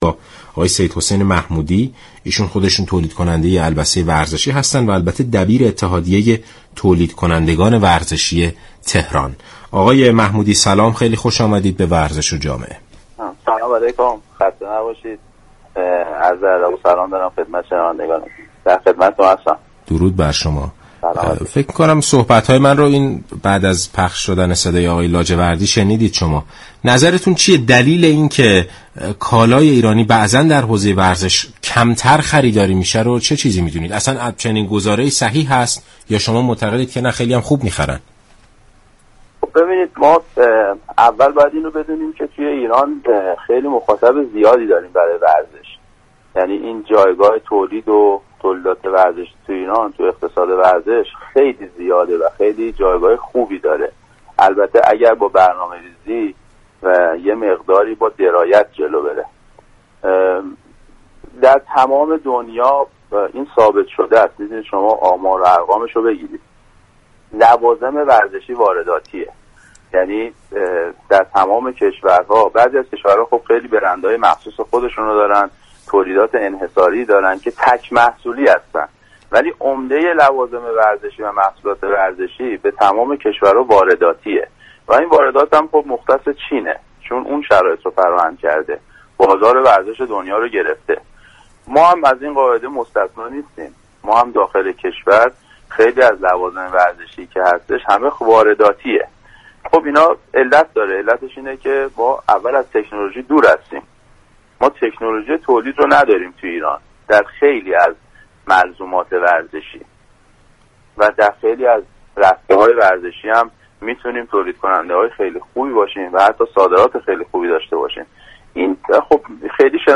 برنامه